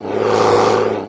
Kodiak Bear Roar That Has Been Rev. In and Out; Exterior